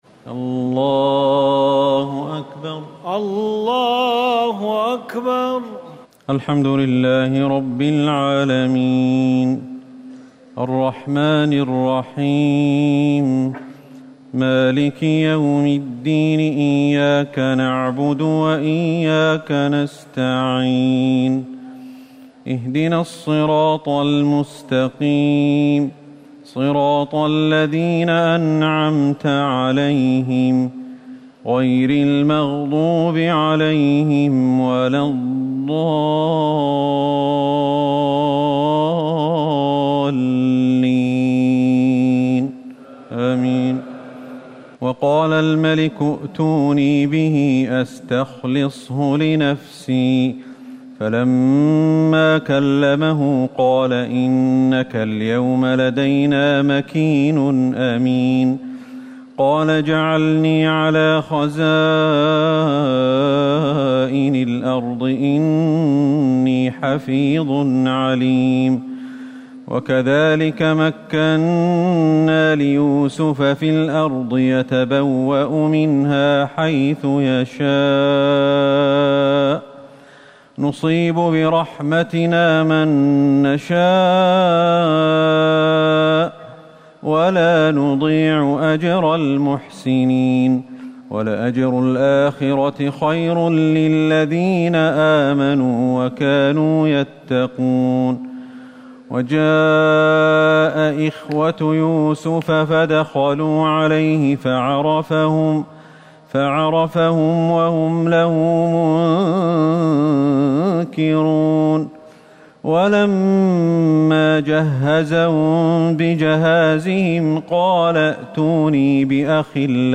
تراويح الليلة الثانية عشر رمضان 1439هـ من سورتي يوسف (54-111) و الرعد (1-17) Taraweeh 12 st night Ramadan 1439H from Surah Yusuf and Ar-Ra'd > تراويح الحرم النبوي عام 1439 🕌 > التراويح - تلاوات الحرمين